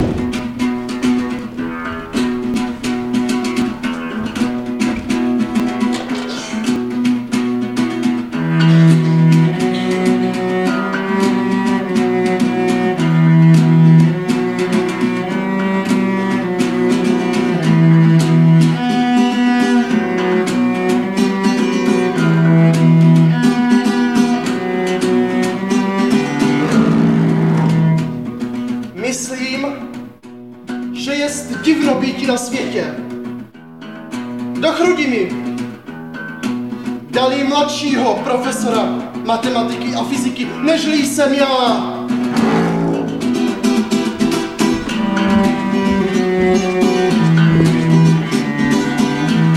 Pábitel a předchůdce Járy Cimrmana Jakub Hron Metánovský v mistrovském podání legendy českého divadla, doplněno i audio záznamem divadelní inscenace (CD; 66:31).